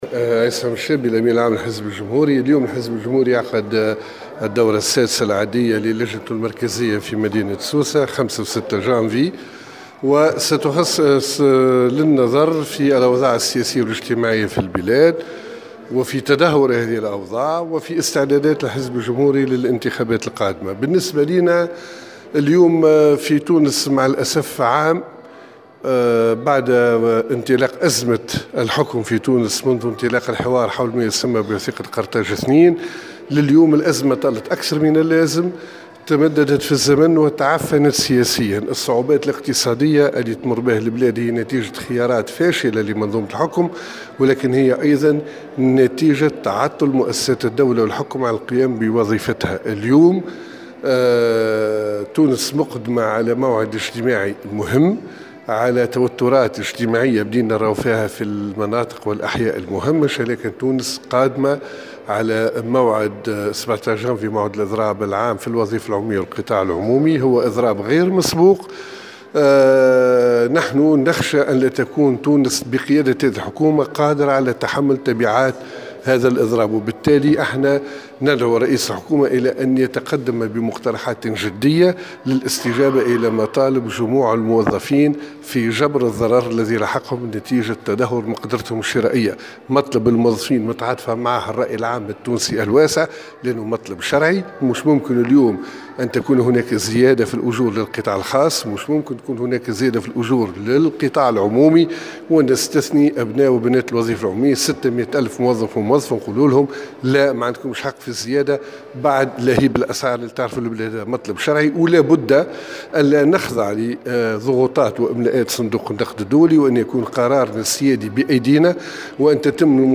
وجاءت تصريحاته على هامش الدورة العادية السادسة للجنة المركزية للحزب الجمهوري المنعقدة يومي 5 و 6 جانفي الحالي بسوسة والتي ستخصص للتداول حول الوضع السياسي والاجتماعي بالبلاد وبحث استعدادات الحزب الجمهوري للمشاركة في الانتخابات القادمة.